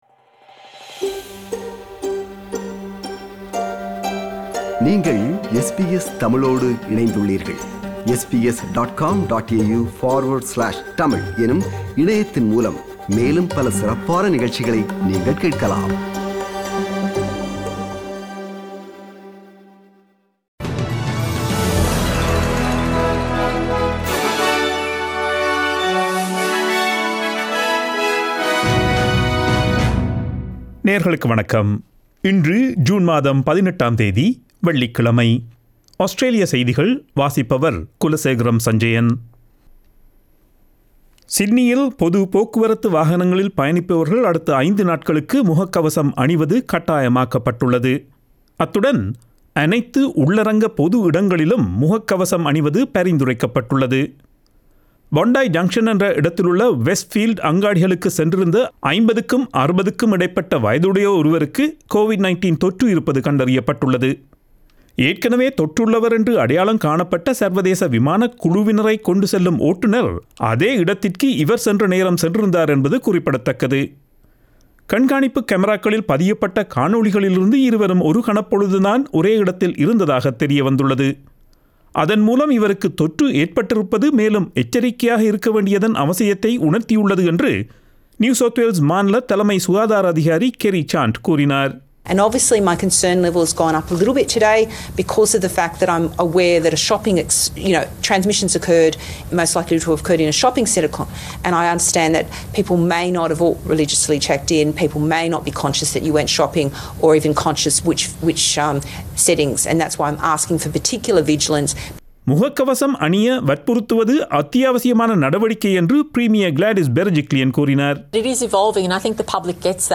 Australian news bulletin for Friday 18 June 2021.